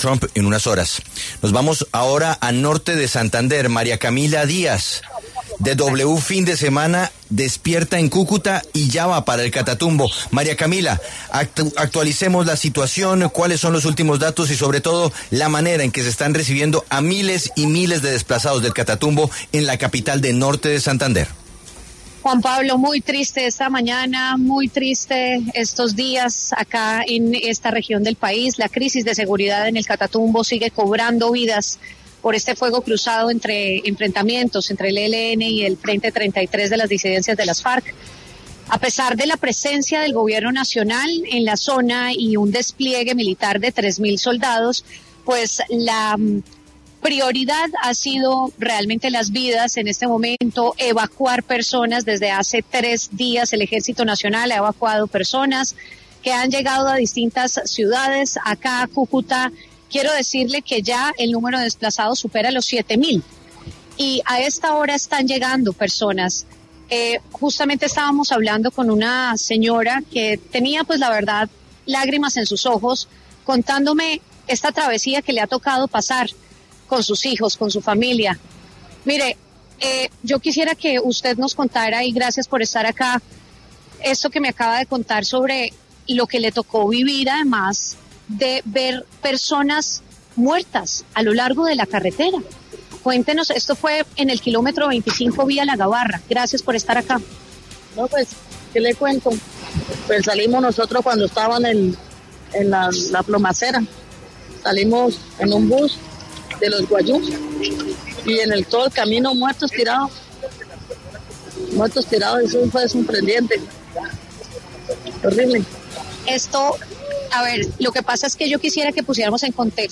Relato de habitantes del Catatumbo
W Radio se encuentra en el Estadio General Santander de Cúcuta, donde siguen llegando decenas de habitantes de la región del Catatumbo que fueron desplazados por los enfrentamientos entre el ELN y las disidencias de las Farc durante más de 4 días.
Una de las habitantes afectadas pasó por los micrófonos de La W y aseguró que aunque esta región siempre ha estado permeada por grupos armados, es la primera experiencia que ven así.